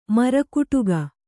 ♪ mara kuṭuga